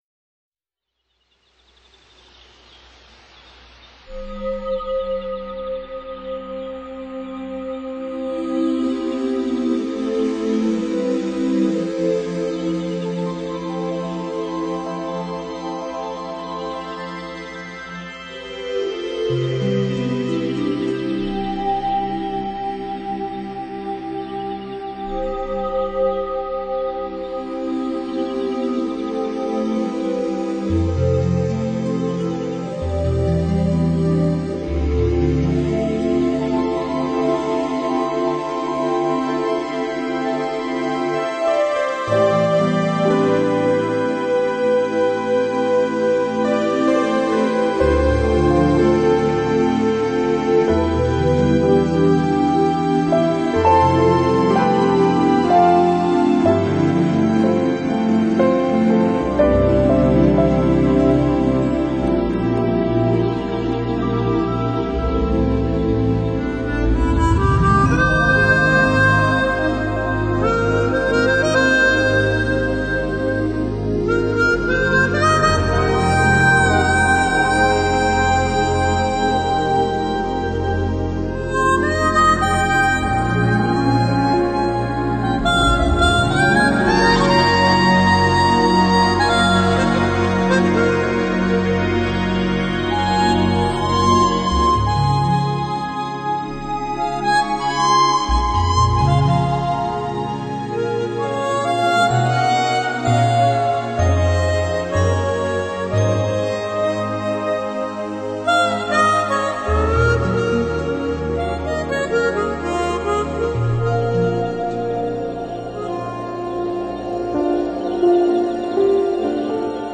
缠绵动人的口琴